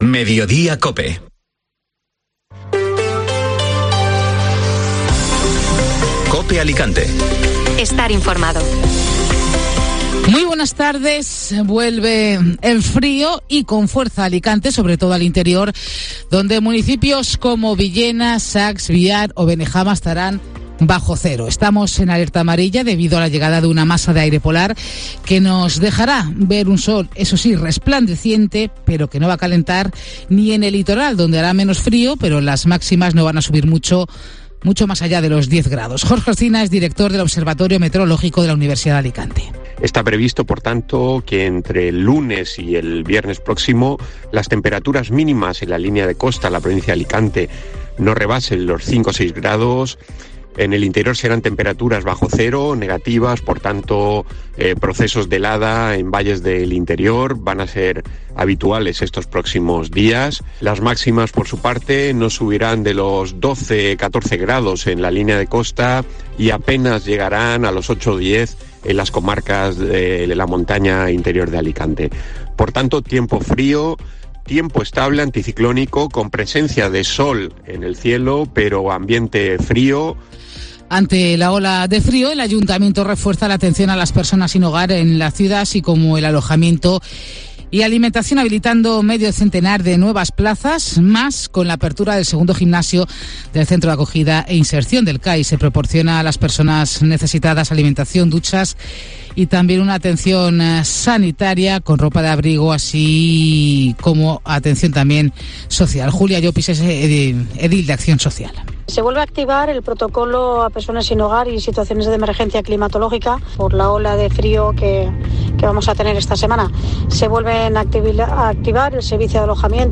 Informativo Mediodía COPE (Lunes 27 de febrero)